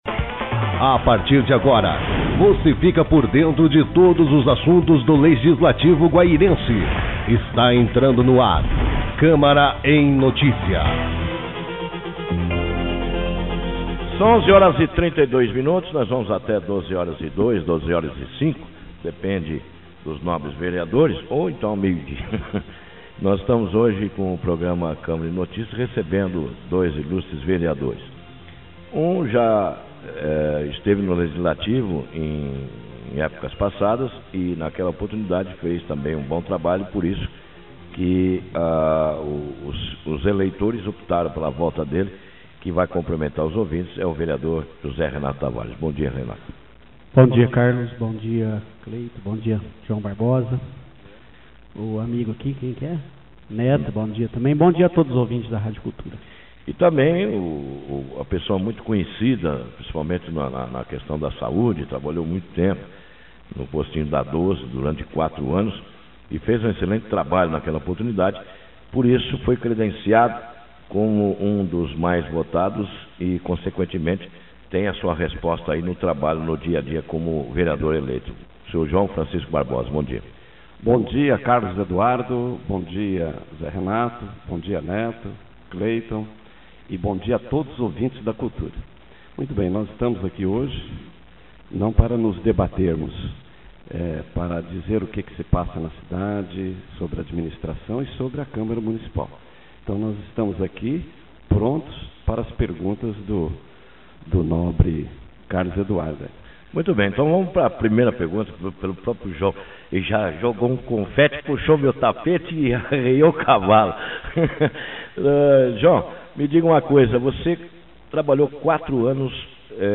Vereadores João Barbosa e José Antônio na Cultura AM